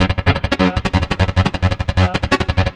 Key-rythm_arp_115.1.1.wav